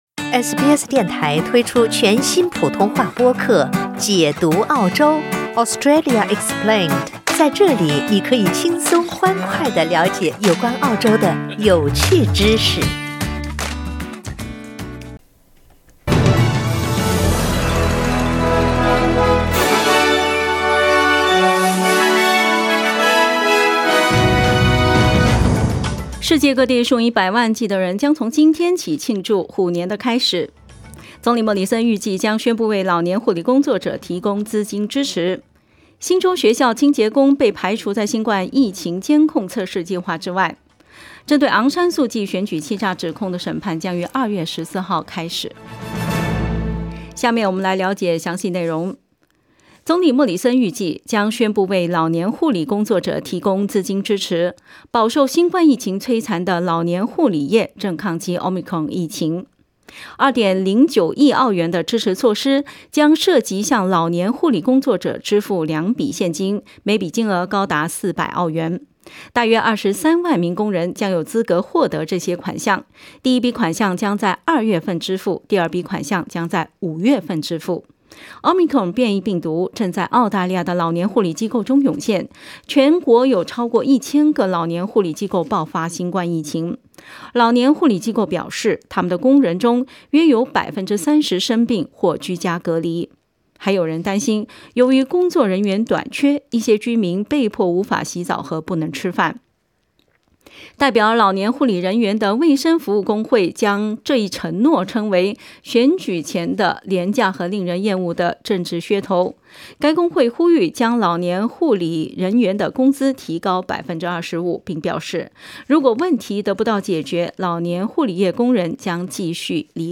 SBS早新闻（2月1日）
SBS Mandarin morning news Source: Getty Images